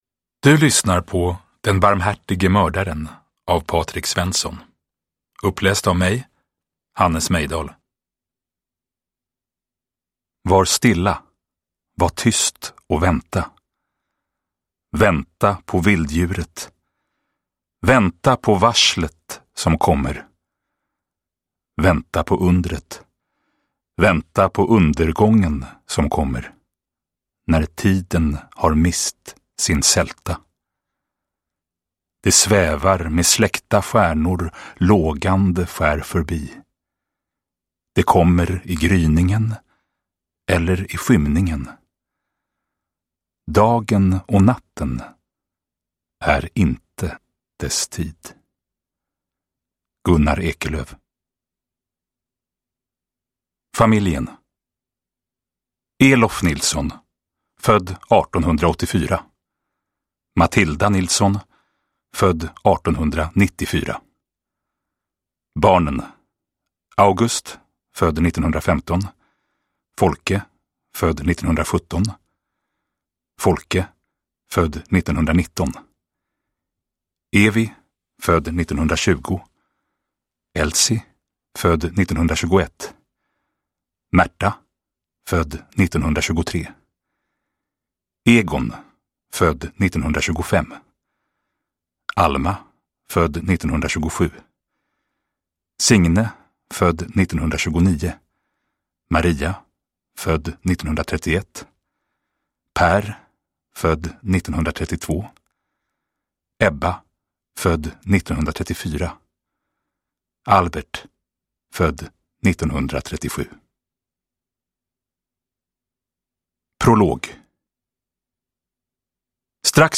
Den barmhärtige mördaren : en berättelse om de sista statarna (ljudbok) av Patrik Svensson